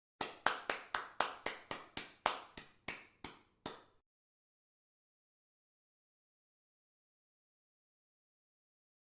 sfx_awkward_clap.mp3